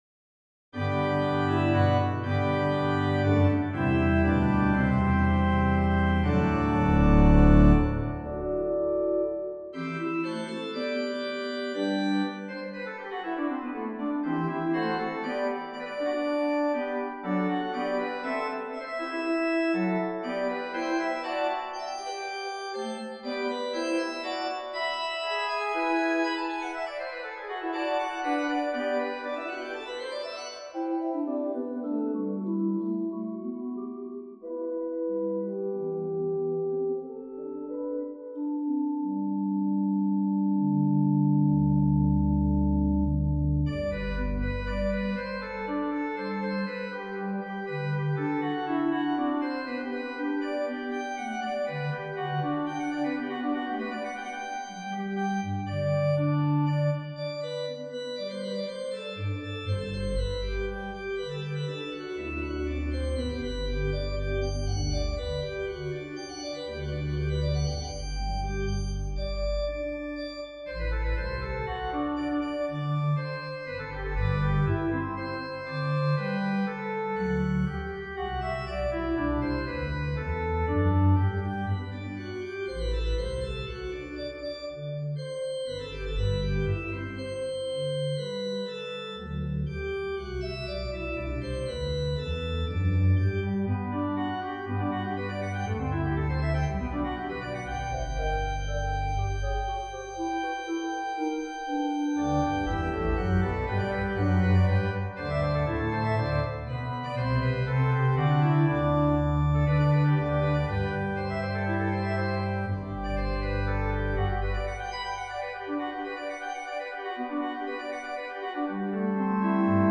for organ
For the summertime, a little joyous musical fun. Many opportunities for registration for the episodic nature, I chose for this emulation to move between flues and mixtures.
The fugue subject, already introduce once in the rondo as part of the structure, is stated alone in the pedal, and then taken up in other voices and tonal domains.